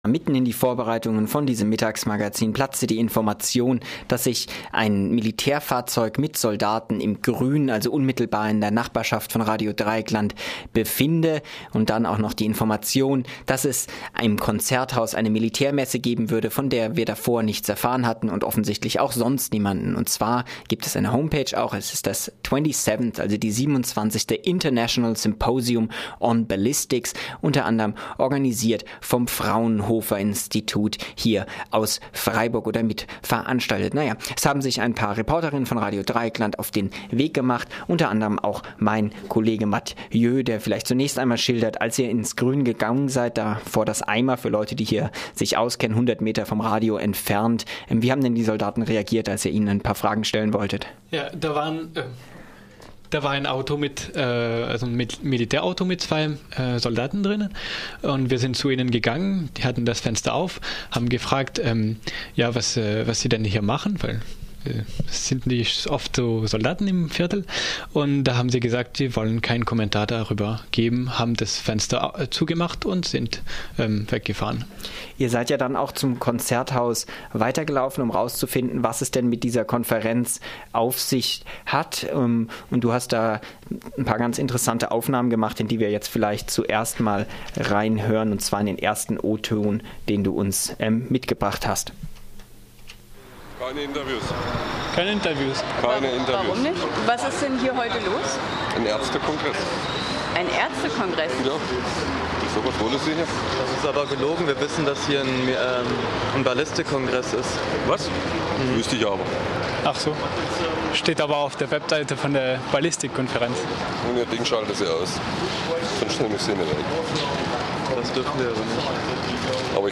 21.10.2013 Ein Bericht mit O-Tönen aus der antimilitaristischen Fahrraddemo, die am Samstag, 19.10. in Freiburg stattfand. Veranstaltet wurde die Demo vom Arbeitskreis gegen Krieg und Militarisierung (AKM).